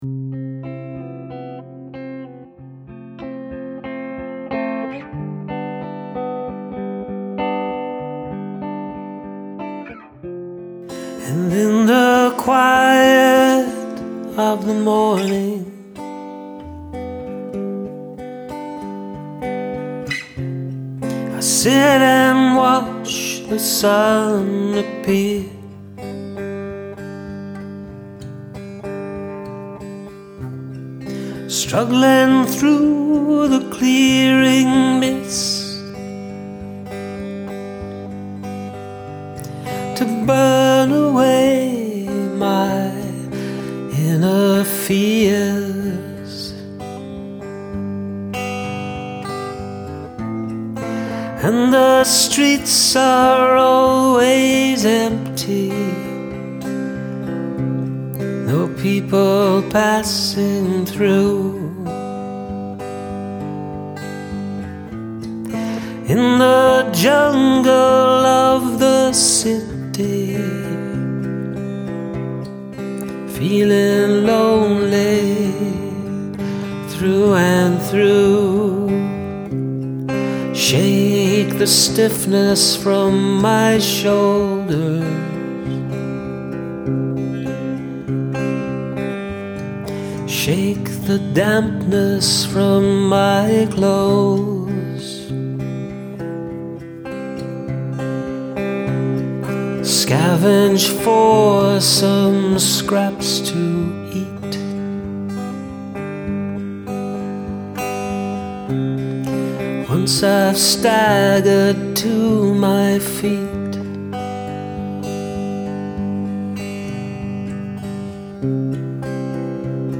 Another quick one-take kind of thingy